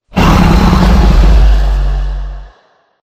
enderdragon
growl2.ogg